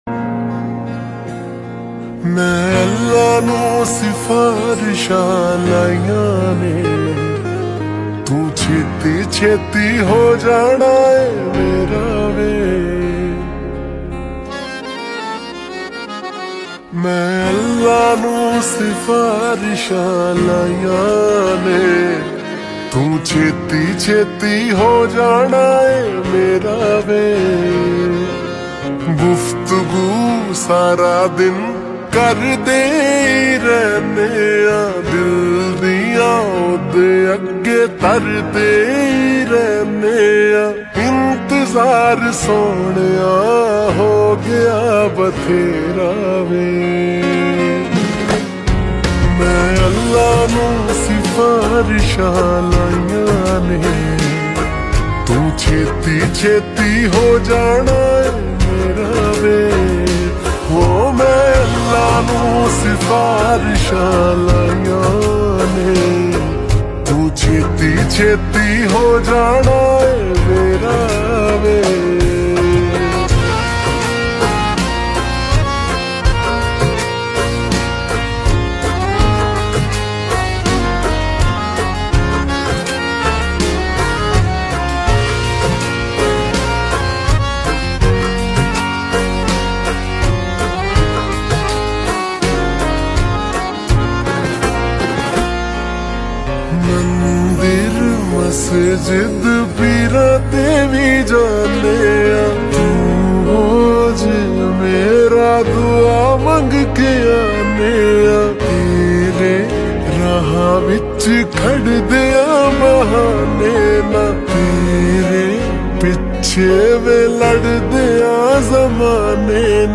{Slowed + Reverb}